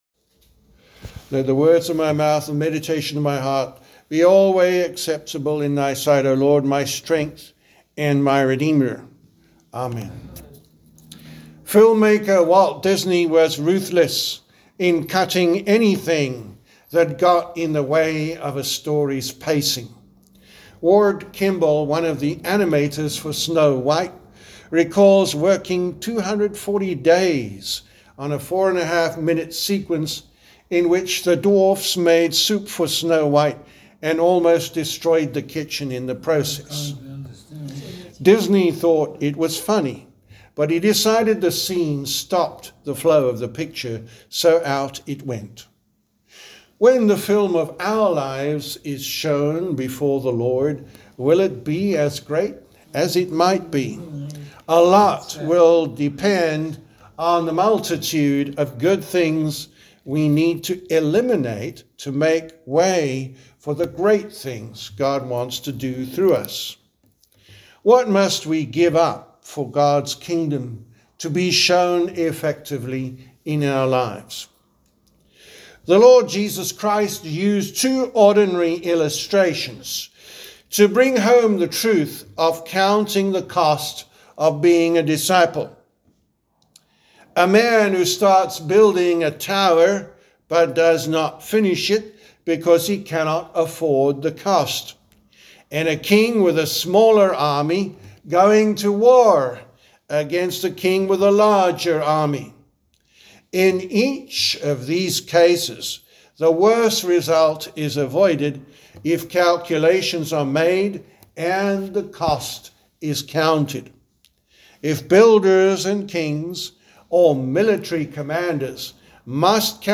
Sermon for Sunday September 7th, 2025, the Twelfth Sunday after Trinity